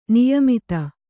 ♪ niyamita